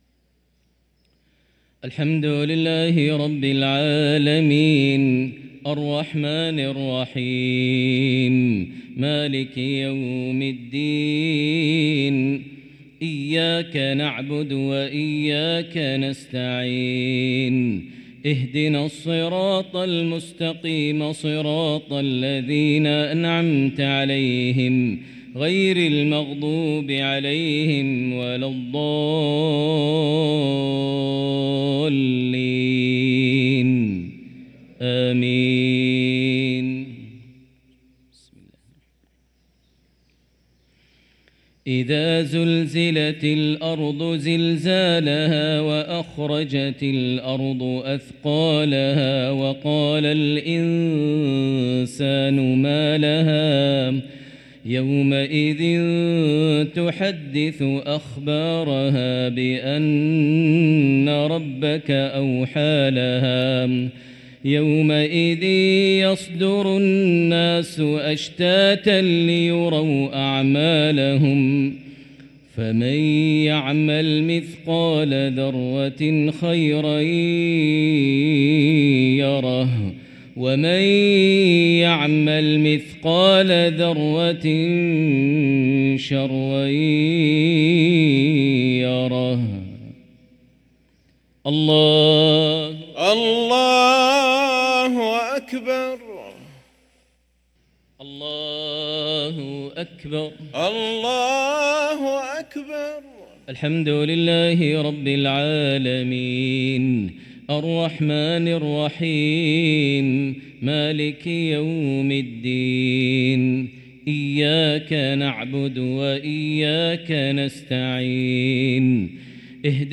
صلاة المغرب للقارئ ماهر المعيقلي 17 جمادي الأول 1445 هـ
تِلَاوَات الْحَرَمَيْن .